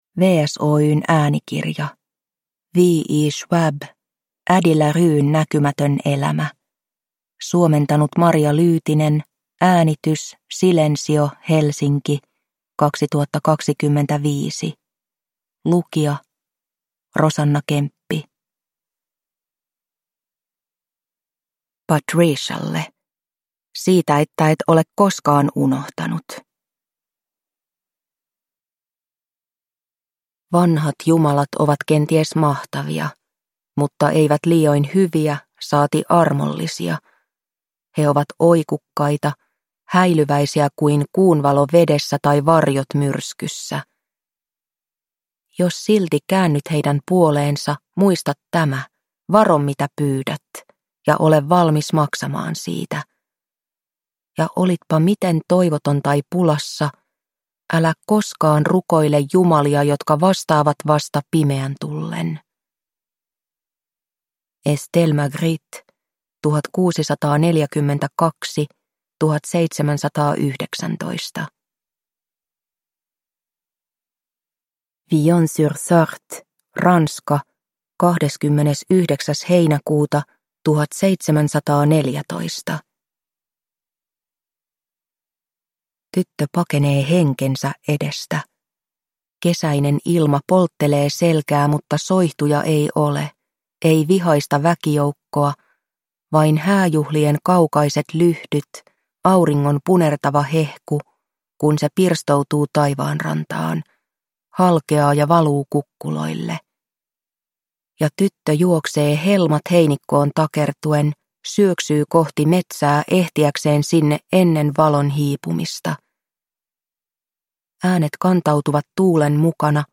Addie LaRuen näkymätön elämä – Ljudbok